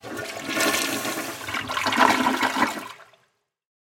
flush.mp3